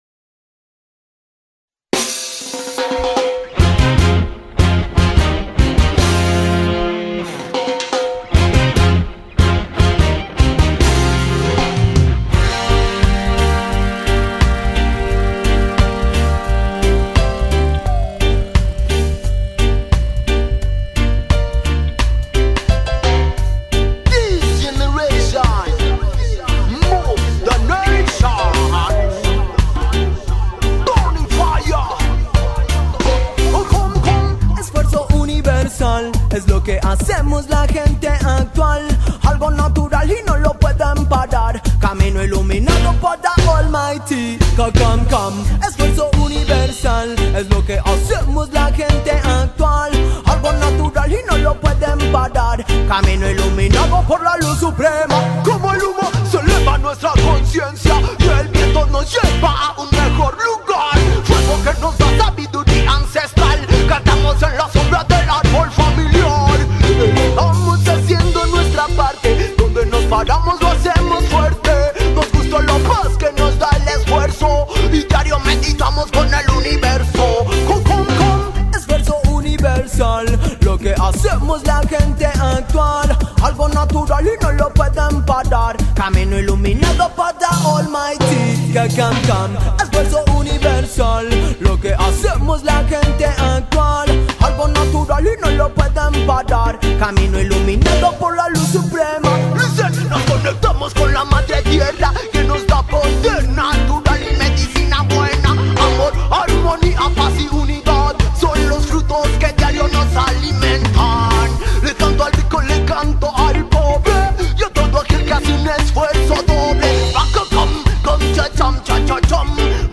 🎙Podcast: Intervista agli Antidoping – Rototom Sunsplash 2025 📻 Conduce
L’intervista, realizzata al Rototom Sunsplash 2025, ci porta nel cuore pulsante della scena reggae latinoamericana.
Artista-a-la-Vista-Intervista-Antidoping.mp3